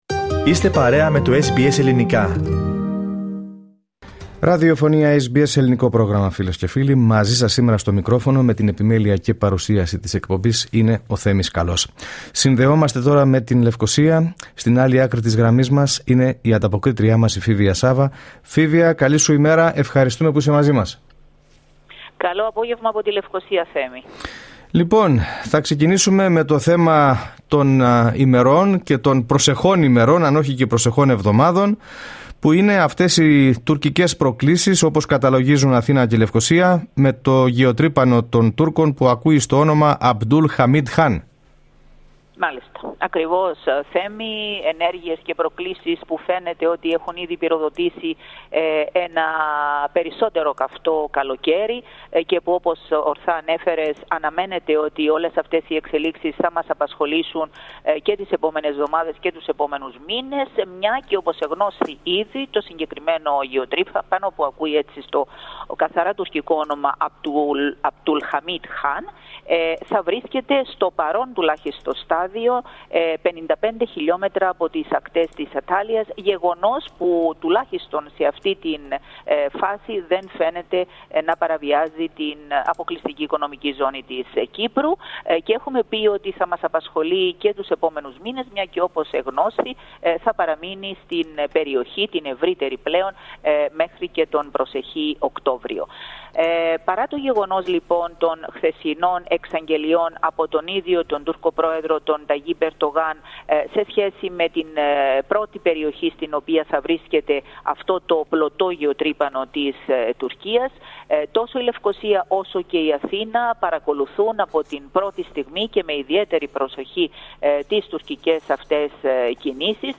Ακούστε γι’ αυτό το θέμα, μεταξύ άλλων, στη σημερινή ανταπόκριση από την Κύπρο, πατώντας play πάνω από την κεντρική φωτογραφία.